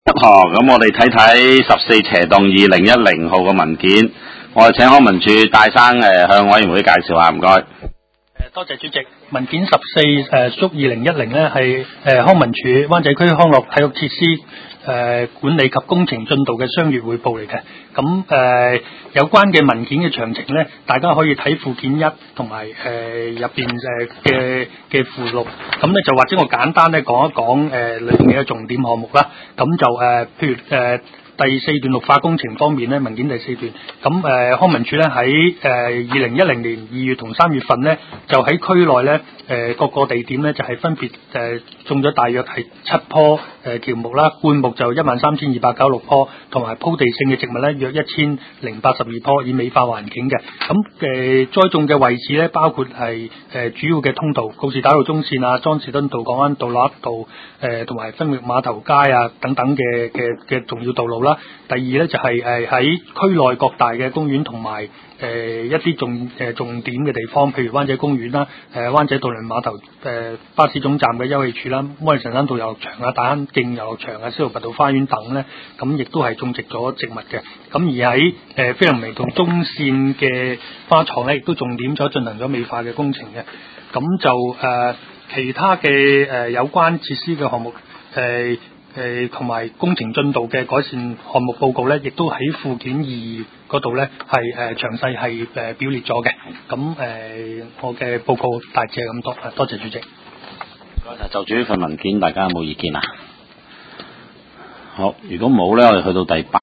地區工程及設施管理委員會第十五次會議
灣仔民政事務處區議會會議室